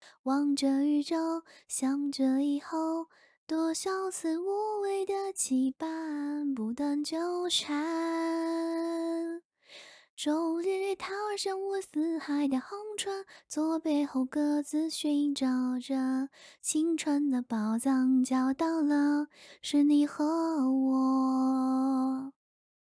AI 免费精品 柔柔，应该算是目前全网流通的最知名的免费模型了，在实时变声、女变女、男变女声的效果强悍，很多家都在用。
免费模型女声模型